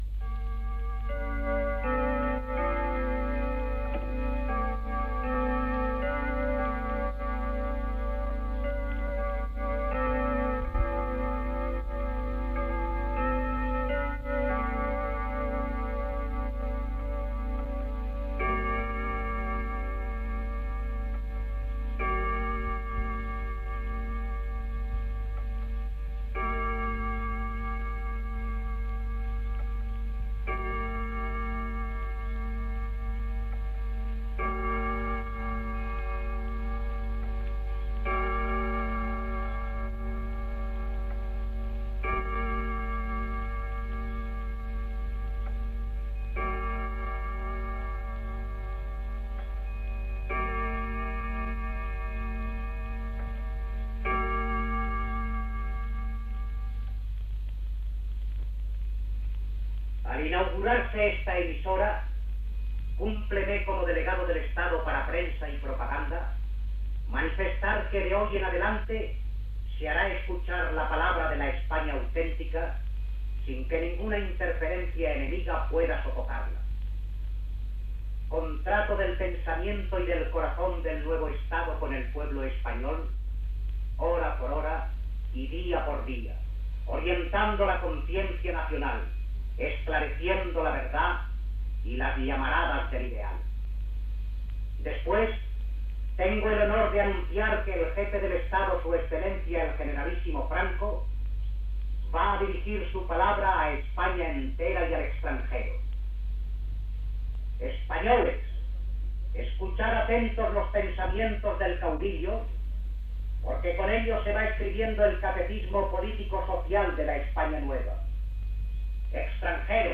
Recreació de la inauguració de Radio Nacional de España a Salamanca: campanades, paraules de Vicente Gay, Delegado del Estado para Prensa y Propaganda, i del "generalísimo" Francisco Franco, himne d'Espanya (Les veus no es corresponen amb les de les persones esmentades)
Recreació de la inauguració de RNE a Salamanca feta l'any 1967 dins del programa "Historia de 30 años. Biografia seriada de RNE"que es va reposar dins del programa "El sonido de la historia", emès per Radio 5 Todo Noticias el dia 2 de febrer de 2013.